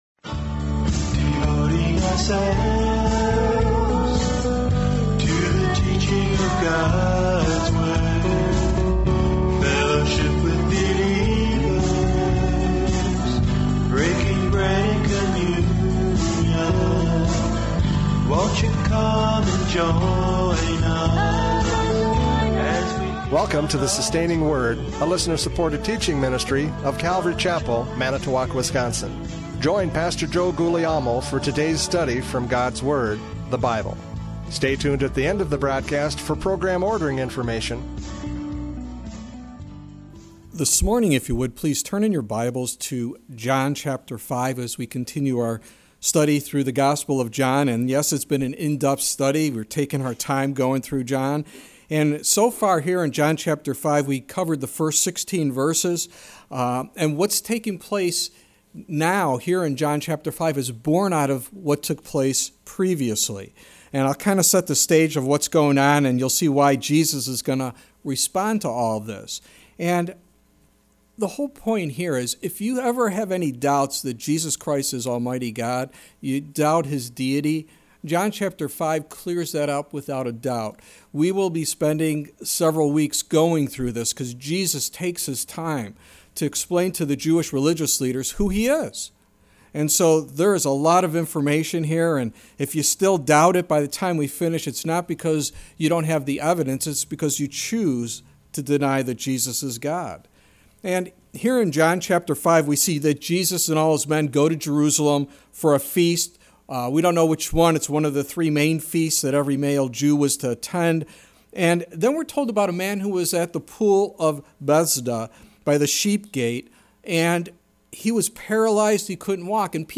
John 5:17-18 Service Type: Radio Programs « John 5:10-16 Legalism Kills!